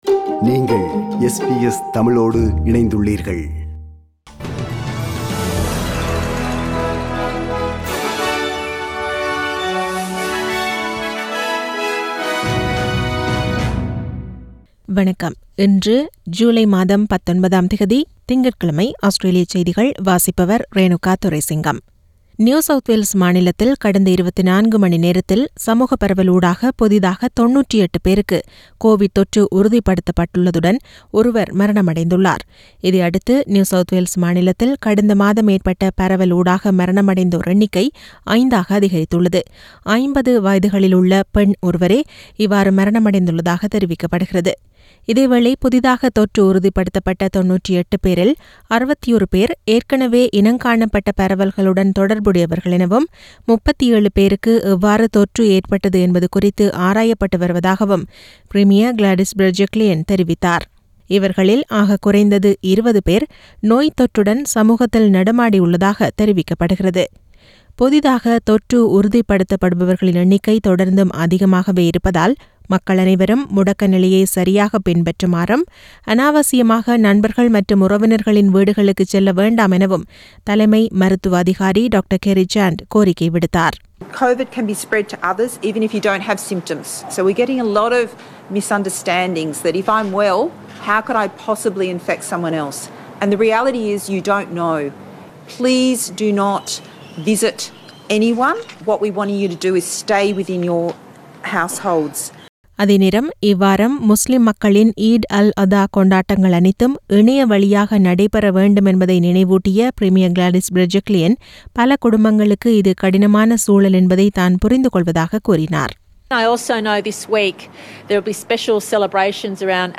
SBS தமிழ் ஒலிபரப்பின் இன்றைய (திங்கட்கிழமை 19/07/2021) ஆஸ்திரேலியா குறித்த செய்திகள்.